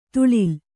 ♪ tuḷil